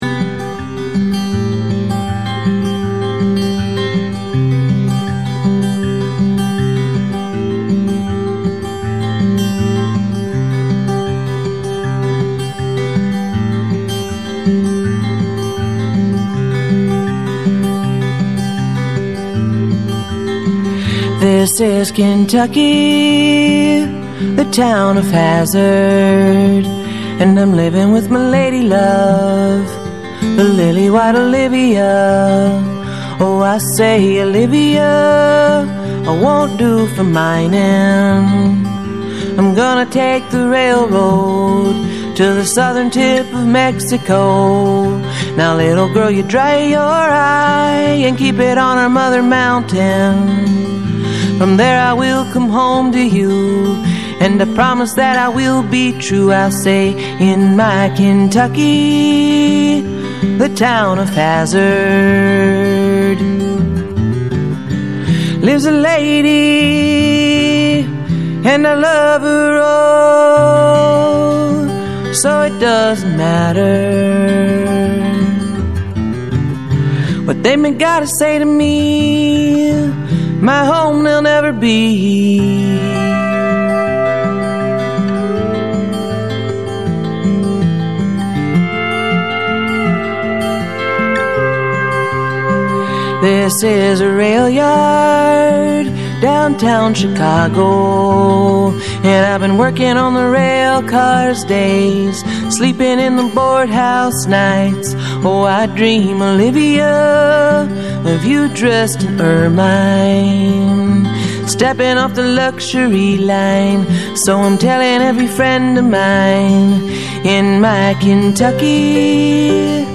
musical guest